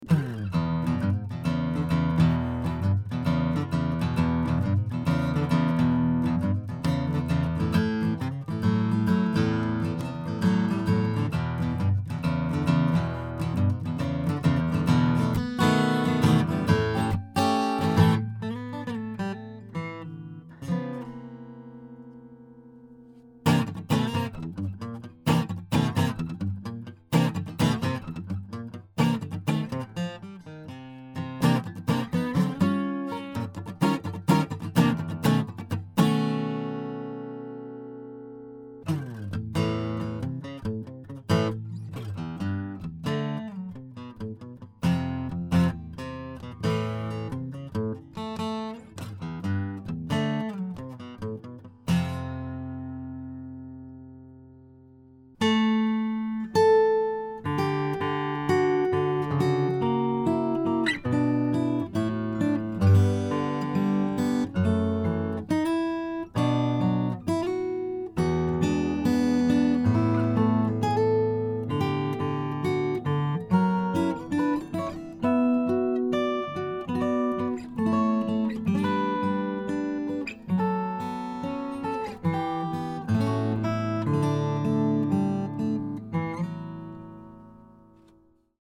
GA 的琴身，是當前眾多結他形狀中最全面的一類，彈唱與 Fingerstyle 皆能夠滿足需要。
F1-GA 風格演奏 DEMO
琴弦 Elixir 磷青銅 16052
面板材料 精選徑切Sitka雲杉